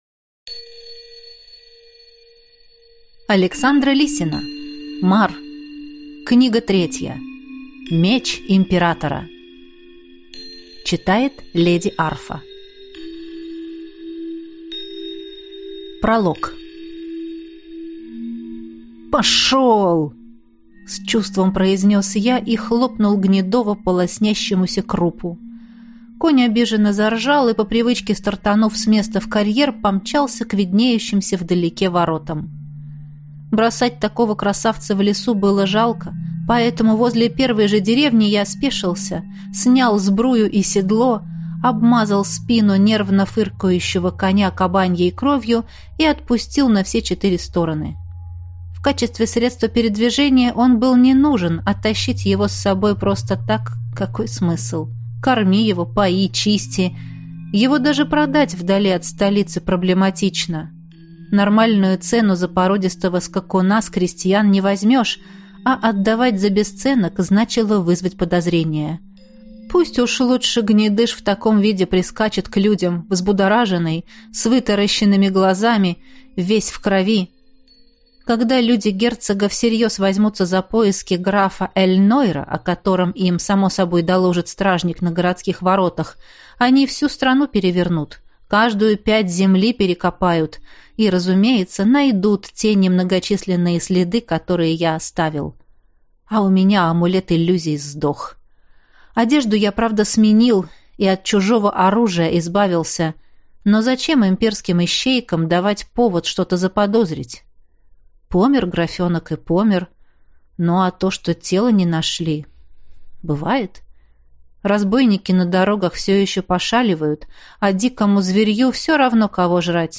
Аудиокнига Мар. Меч императора | Библиотека аудиокниг